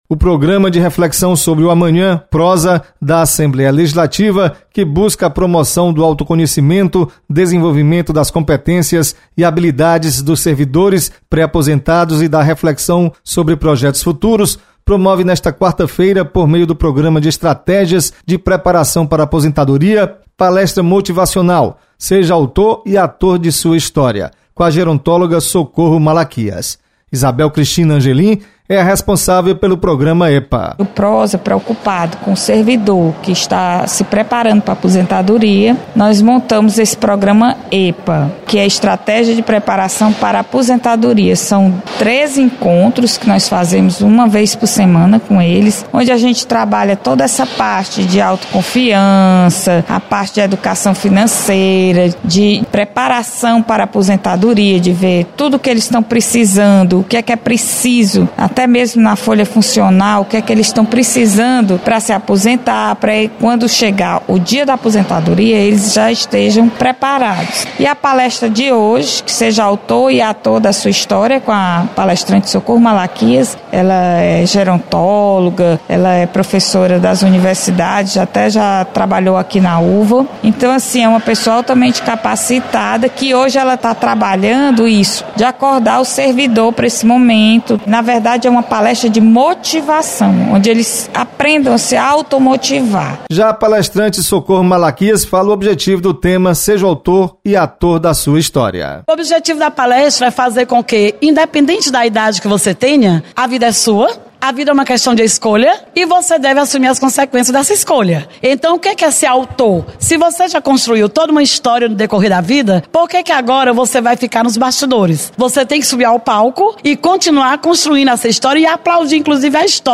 Você está aqui: Início Comunicação Rádio FM Assembleia Notícias PROSA